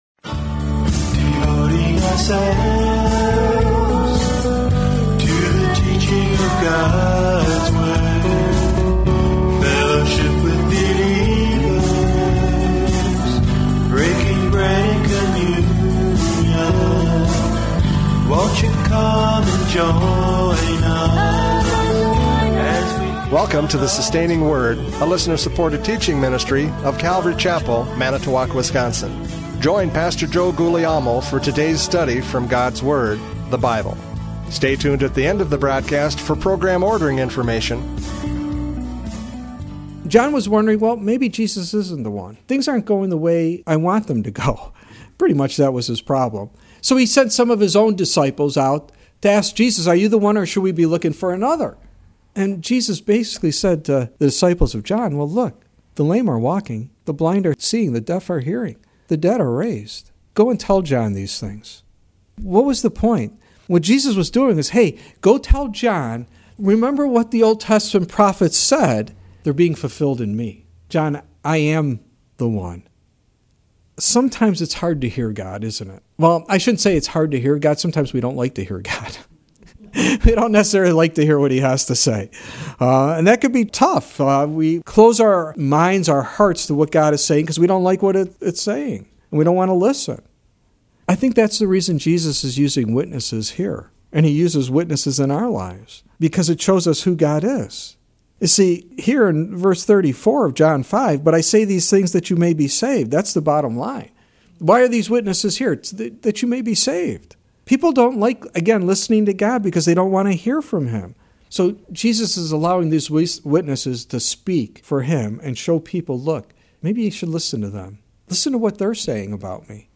John 5:31-35 Service Type: Radio Programs « John 5:31-35 Testimony of John the Baptist!